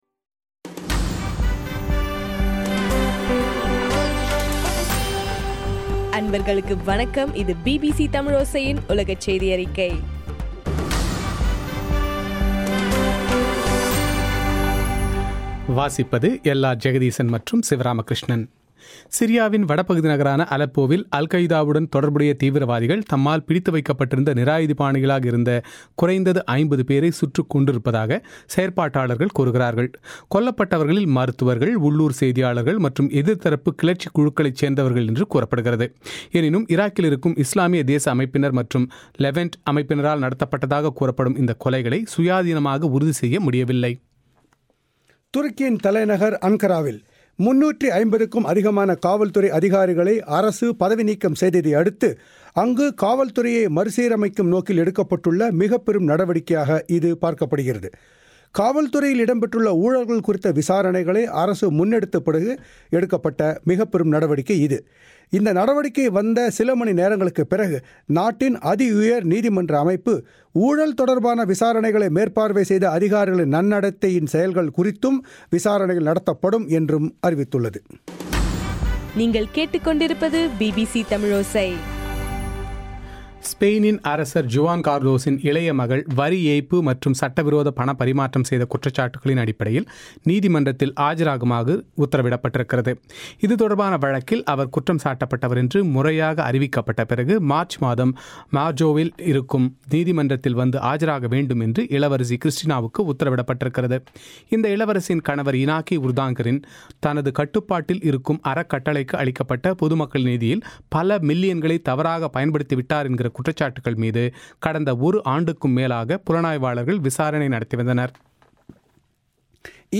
ஜனவரி 7 பிபிசியின் உலகச் செய்திகள்